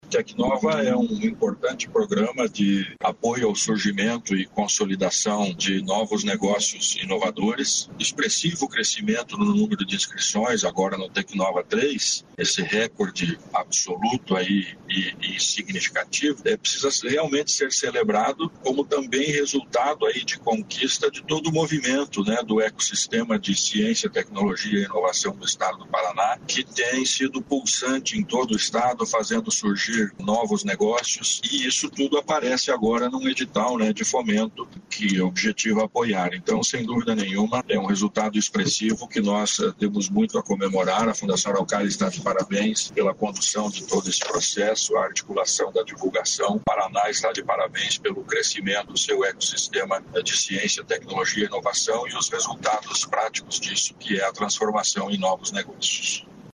O secretário estadual da Ciência, Tecnologia e Ensino Superior, Aldo Bona, sobre o Tecnova III